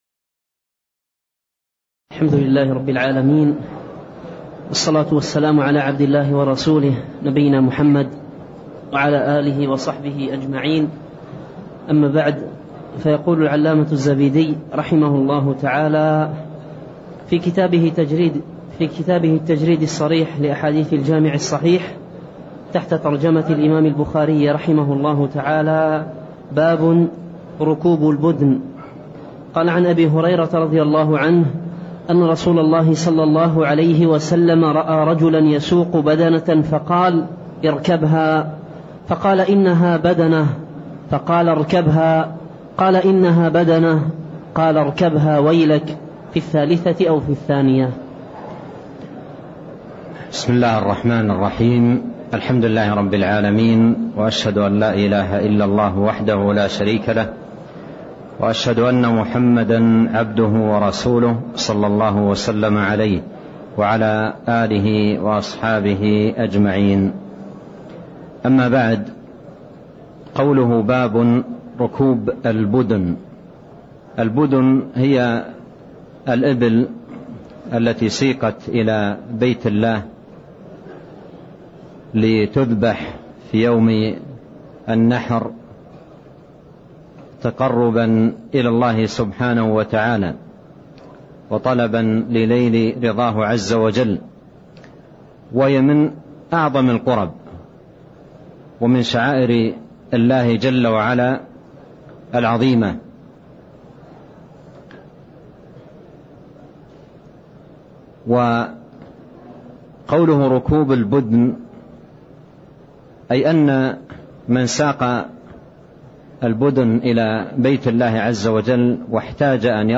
تاريخ النشر ٢٣ ذو القعدة ١٤٣٤ هـ المكان: المسجد النبوي الشيخ